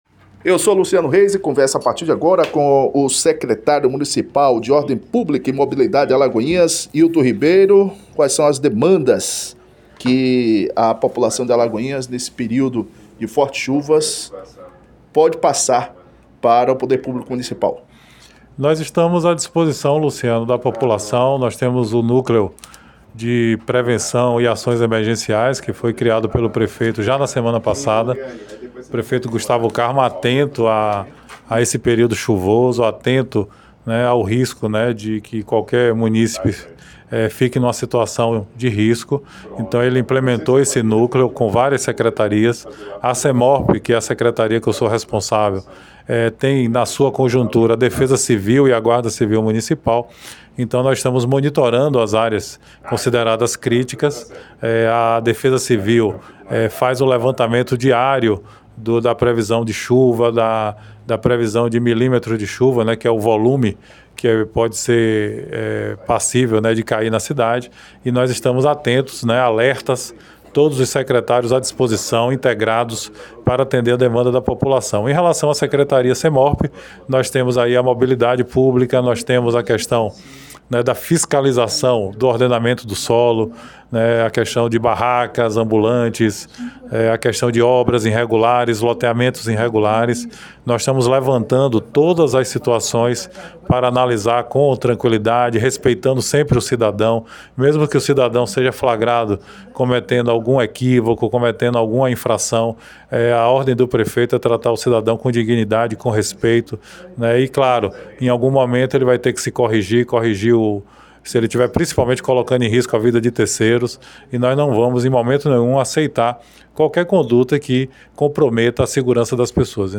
Ouça à integra da entrevista do secretário de Mobilidade e Ordem Pública de Alagoinhas, Hilton Ribeiro